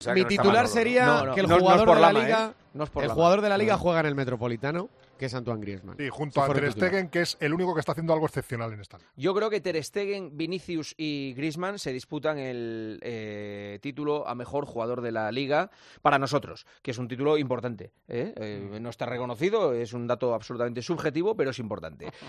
AUDIO: El presentador de 'El Partidazo de COPE' señala quiénes son esos futbolistas que están marcando las diferencias durante esta edición